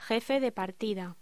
Locución: Jefe de partida
voz